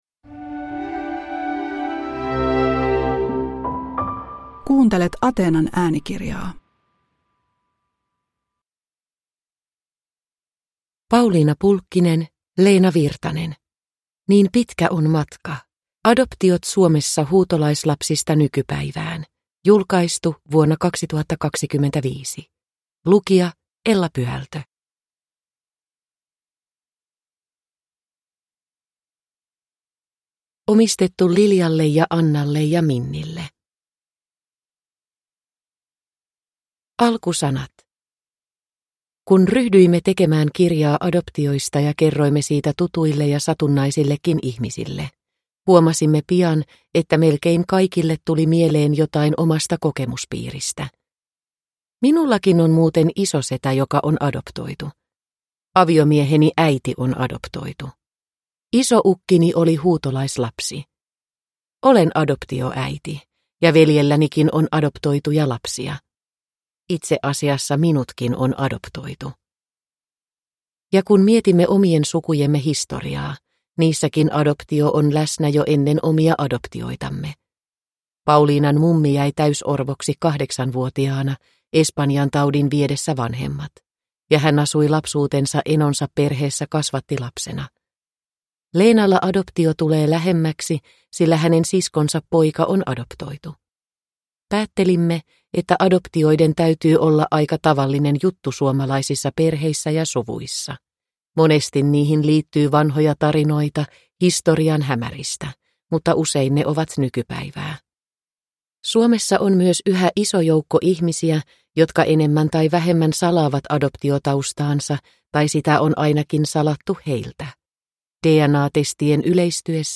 Niin pitkä on matka (ljudbok) av Leena Virtanen